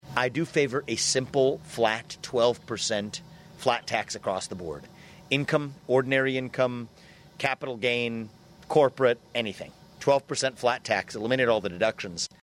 RAMASWAMY MADE HIS COMMENTS DURING A RECENT INTERVIEW WITH RADIO IOWA.